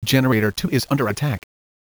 generator_attack_2.wav